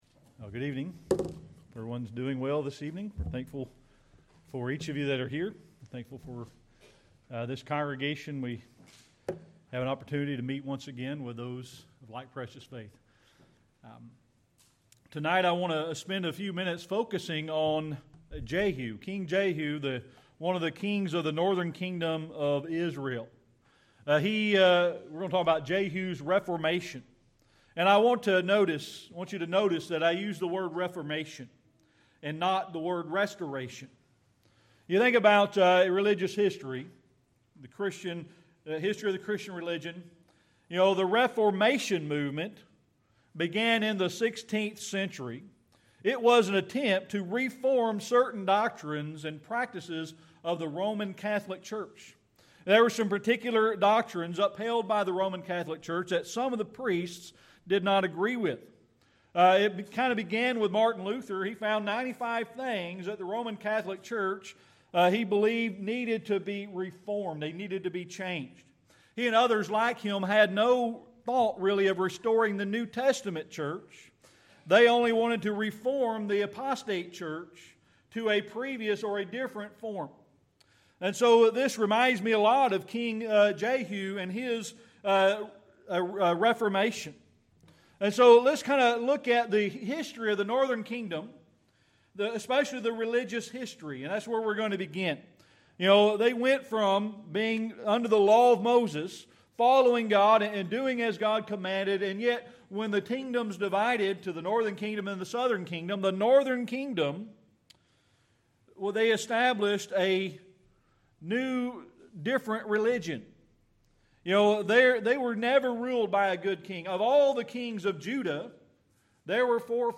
1 Kings 12:25-33 Service Type: Sunday Evening Worship Jehu was one of the kings of the Northern Kingdom of Israel.